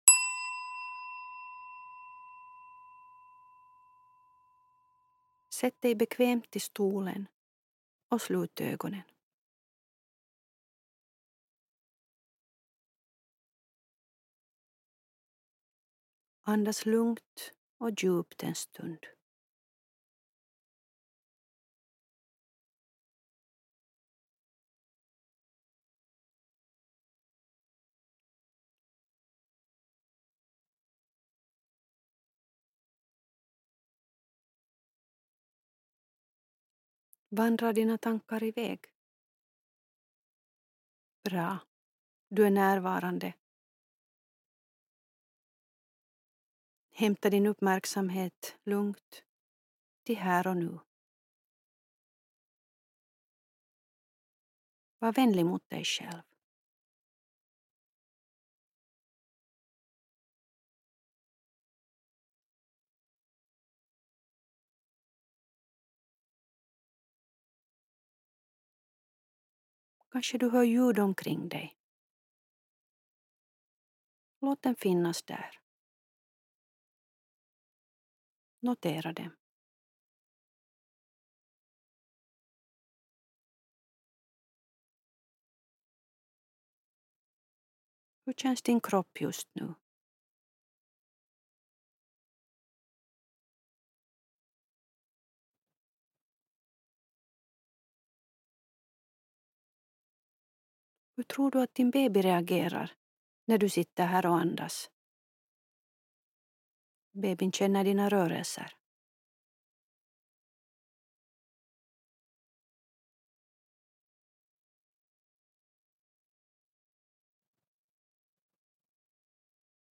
I den här avslappningsövningen får du fundera på resurserna i din omgivning.